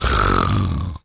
pgs/Assets/Audio/Animal_Impersonations/pig_sniff_long_02.wav at master
pig_sniff_long_02.wav